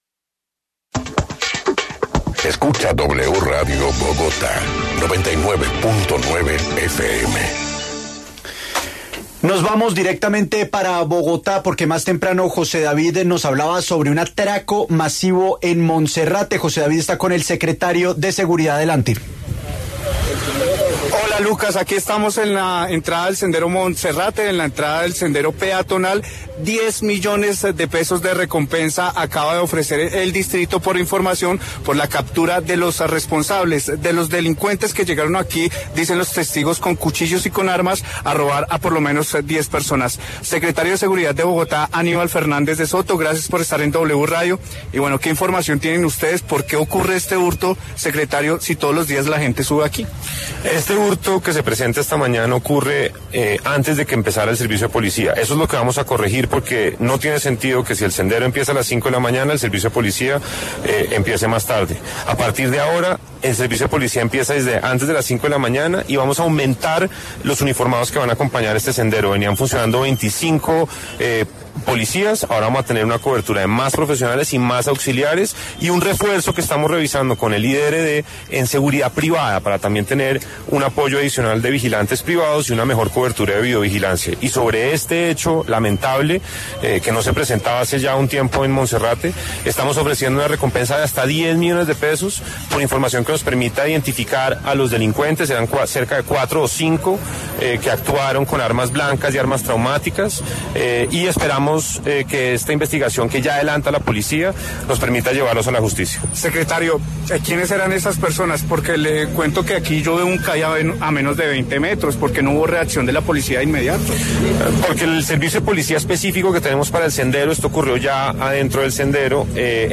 En el encabezado escuche la entrevista completa con Aníbal Fernández De Soto, secretario de Seguridad de Bogotá.